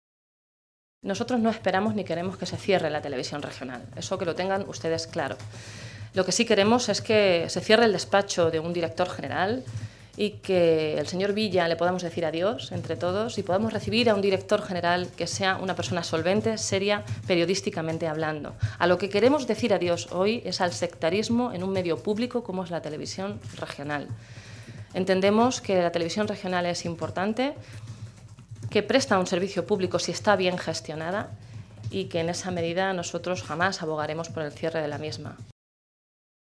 Blanca Fernández, secretaria segunda de la Mesa de las Cortes y diputada regional, en rueda de prensa
Cortes de audio de la rueda de prensa